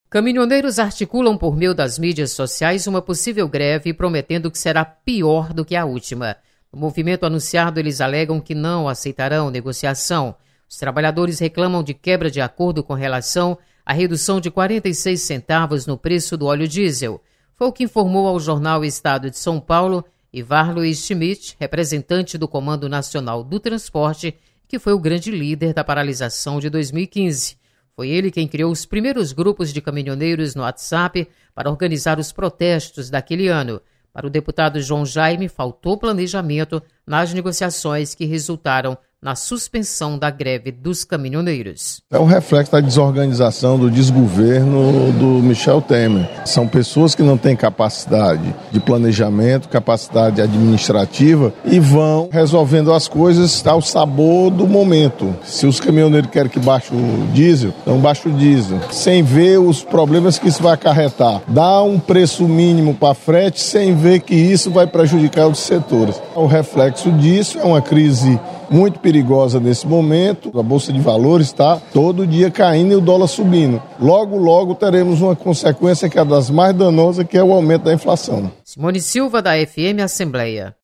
Deputado João Jaime mostra preocupação com possibilidade de nova greve dos caminhoneiros. Repórter